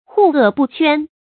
注音：ㄏㄨˋ ㄝˋ ㄅㄨˋ ㄑㄨㄢ
怙惡不悛的讀法